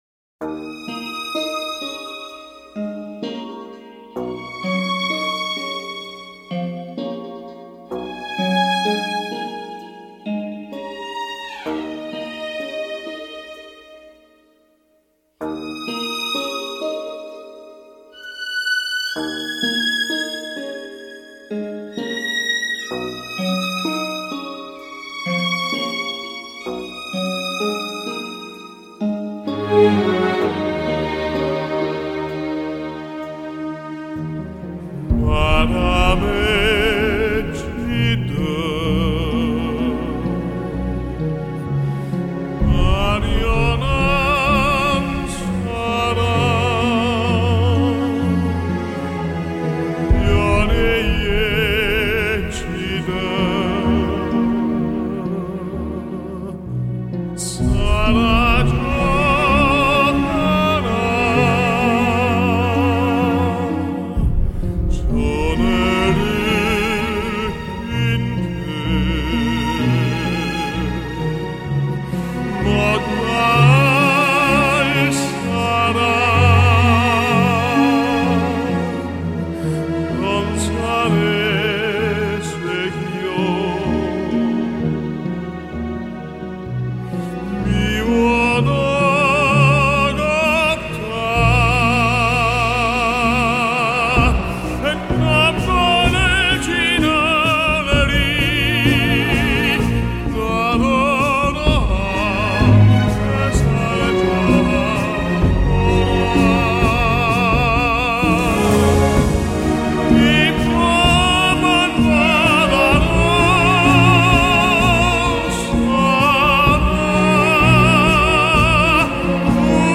饱满嘹亮的漂亮男高音音色
带著浓厚义大利风味的流行歌剧